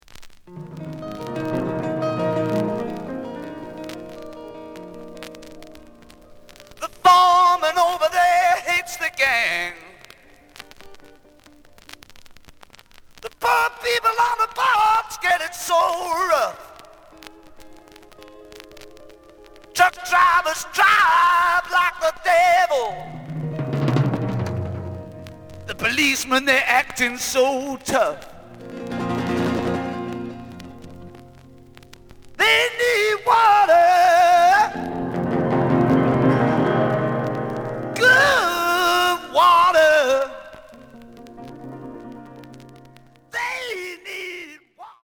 The audio sample is recorded from the actual item.
●Format: 7 inch
●Genre: Rock / Pop
Looks good, but slight noise on both sides.